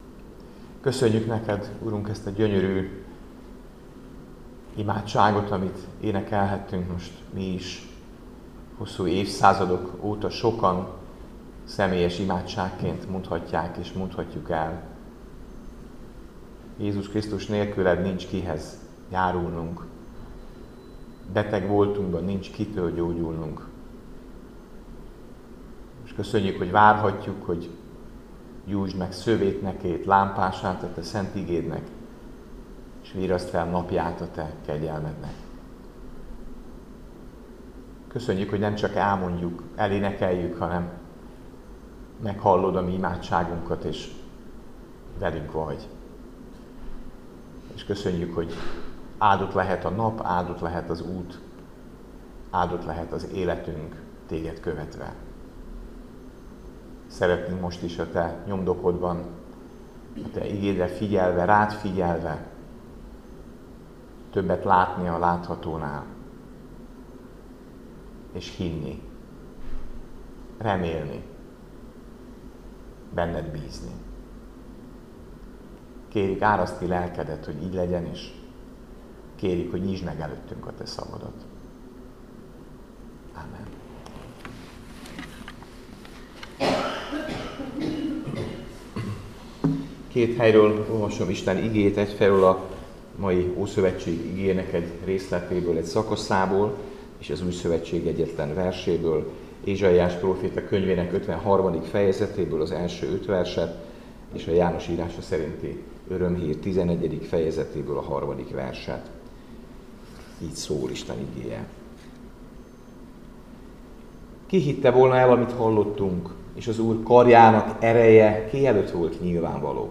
Áhítat, 2025. december 9.